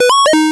retro_synth_beeps_03.wav